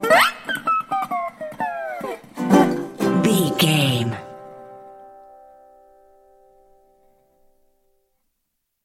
Ionian/Major
acoustic guitar
percussion